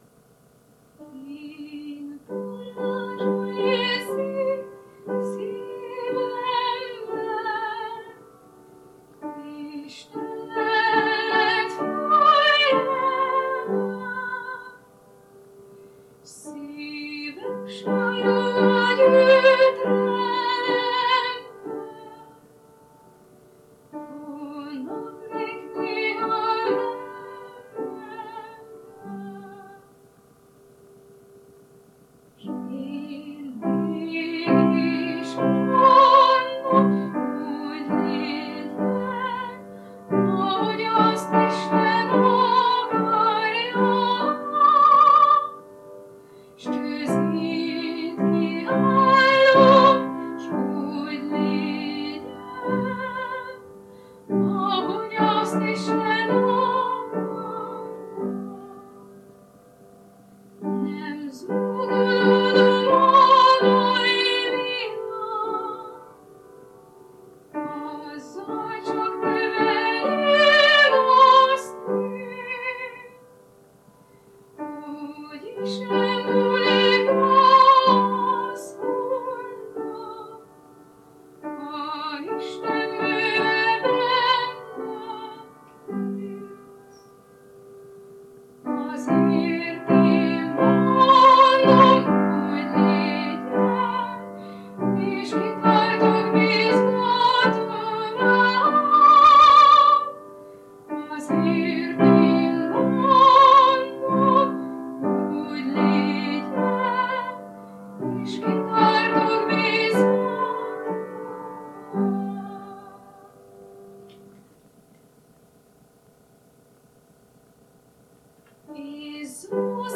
Ének
Zongora